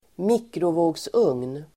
Uttal: [²m'ik:rovå:gsung:n]